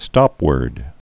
(stŏpwûrd)